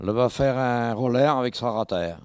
Localisation Epine (L')
Catégorie Locution